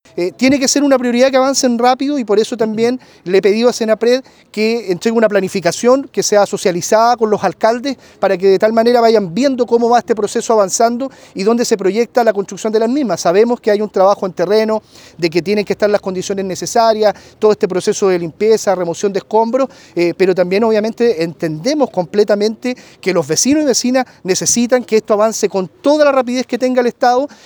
El delegado Presidencial en el Bío Bío, Eduardo Pacheco, respondió en voz del Gobierno.